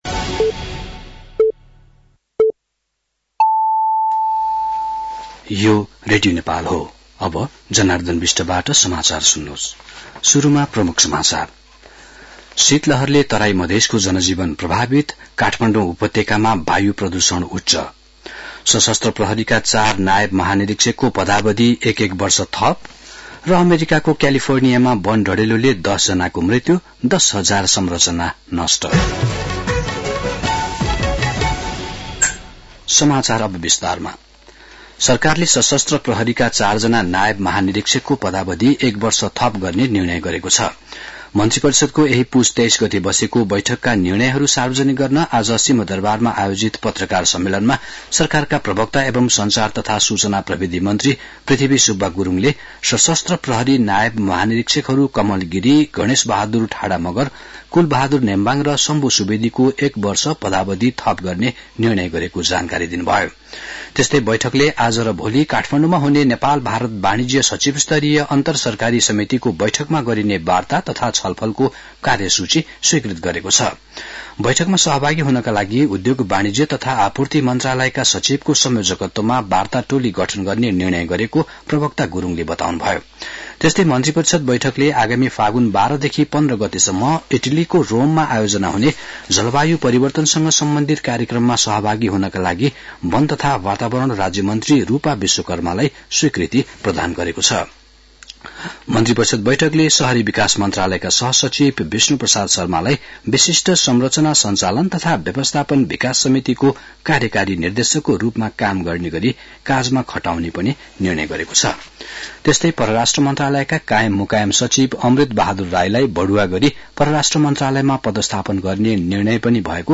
दिउँसो ३ बजेको नेपाली समाचार : २७ पुष , २०८१
3-pm-nepali-news-1.mp3